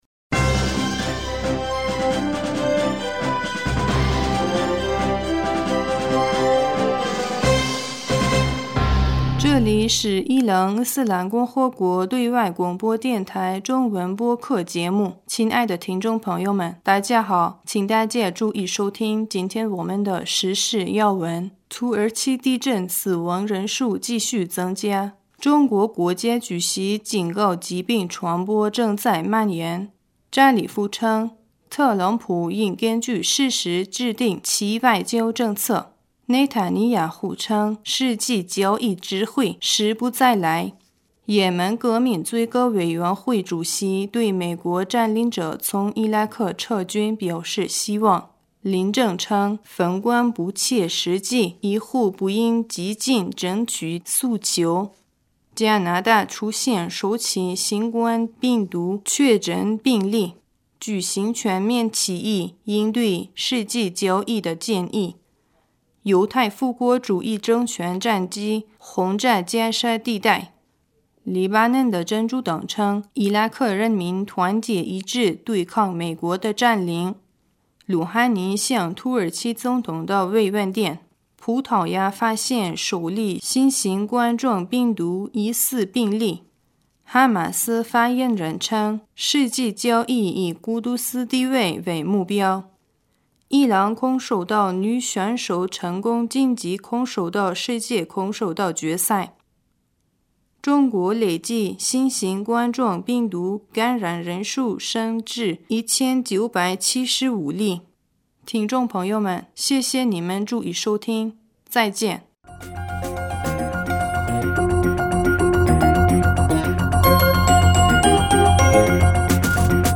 2020年1月26日 新闻